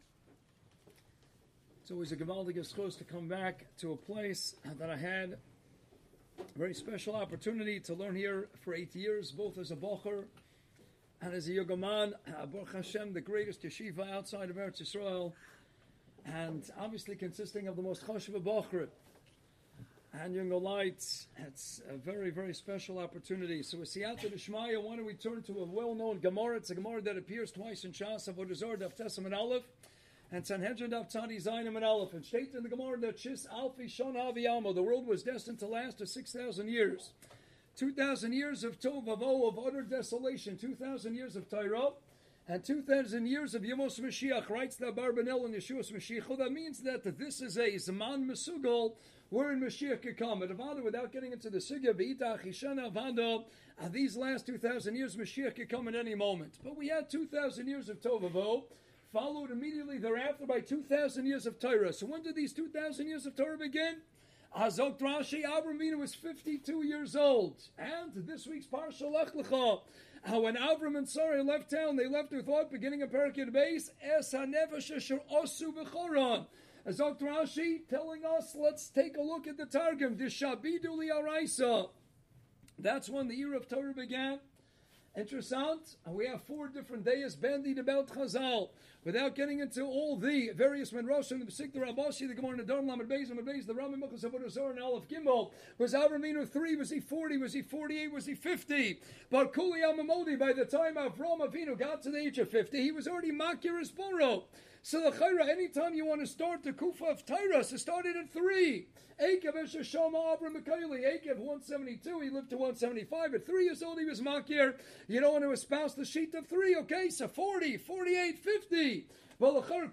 Special Lecture, Yeshiva - Ner Israel Rabbinical College